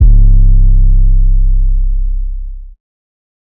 808s
BWZYSub_4.wav